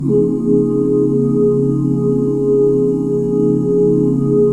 DBMAJ7 OOO.wav